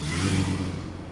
汽车的声音 " 汽车中走
描述：汽车在街上经过，柔和的引擎声。
Tag: 汽车 汽车 驾驶 现场录音 街道 汽车